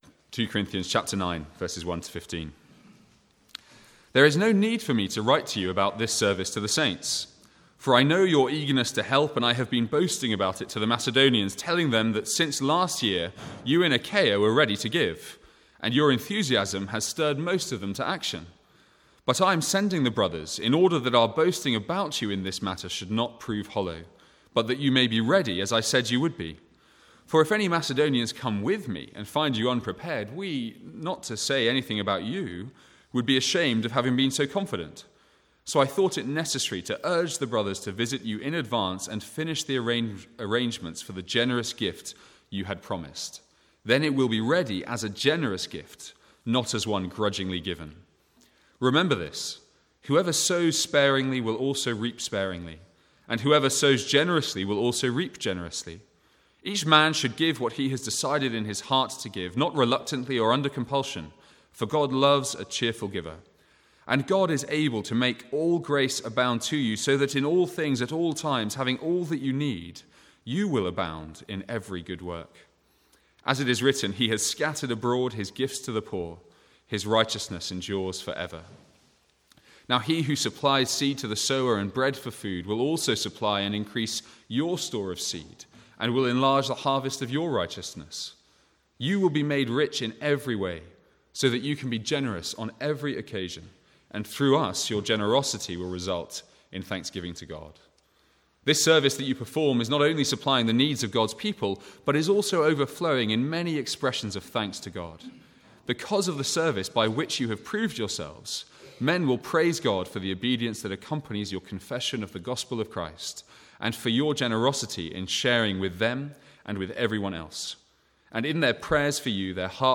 Sermons | St Andrews Free Church
From the Sunday morning series in 2 Corinthians.